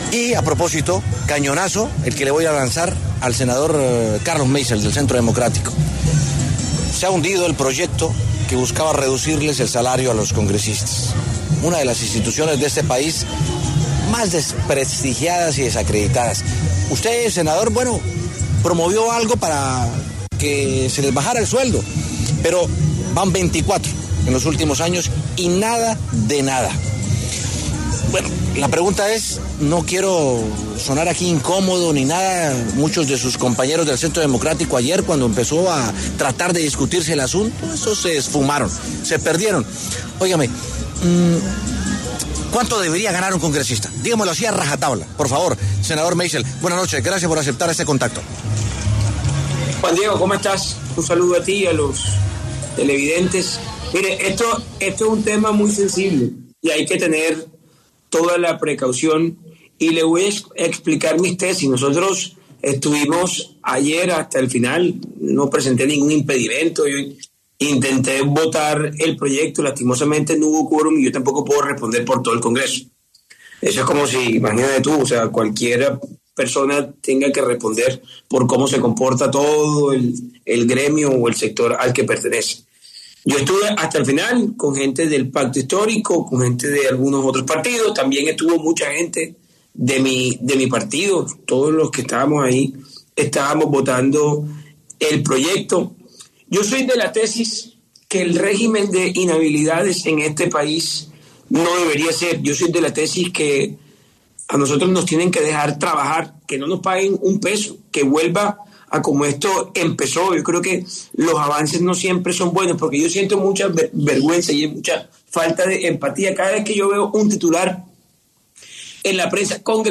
Para hablar sobre la coyuntura, pasó por los micrófonos de W Sin Carreta el senador del Centro Democrático Carlos Meisel.